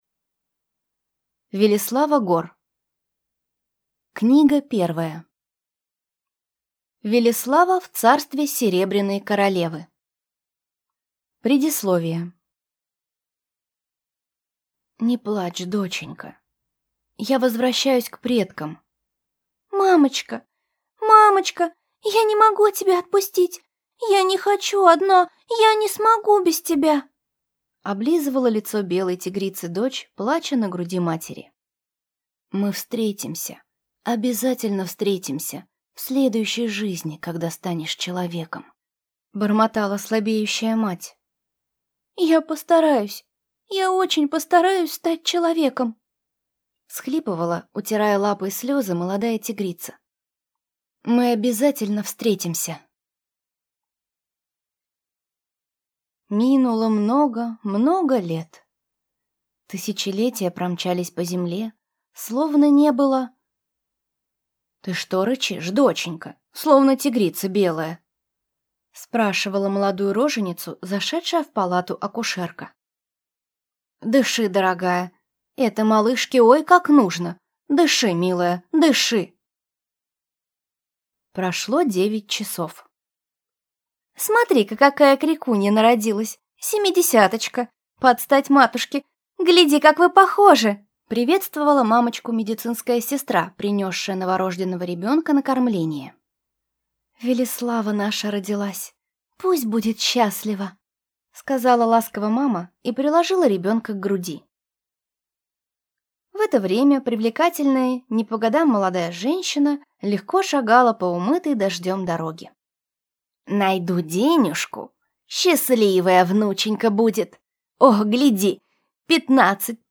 Аудиокнига Велеслава в Царстве Серебряной Королевы | Библиотека аудиокниг